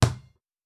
Foley Sports / Basketball / Generic Bounce Distant.wav
Generic Bounce Distant.wav